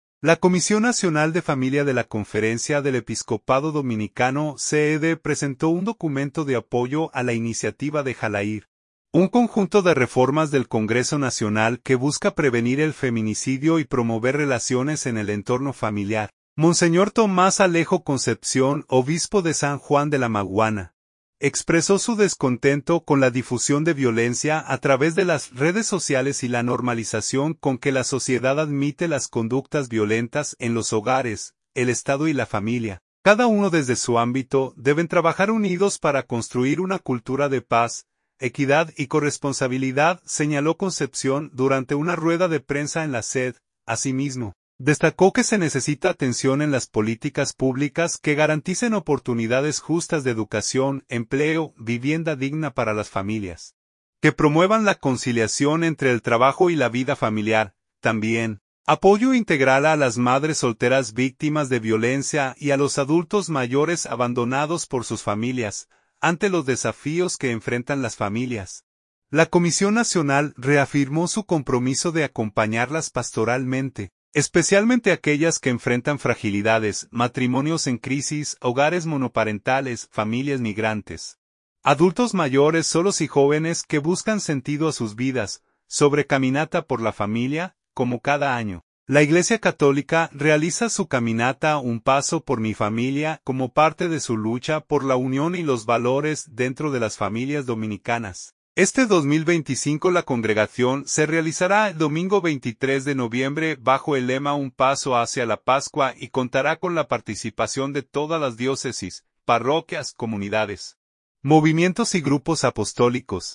"El Estado y la familia, cada uno desde su ámbito, deben trabajar unidos para construir una cultura de paz, equidad y corresponsabilidad", señaló Concepción durante una rueda de prensa en la CED.